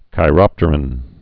(kī-rŏptər-ən) also chi·rop·ter (-rŏptər)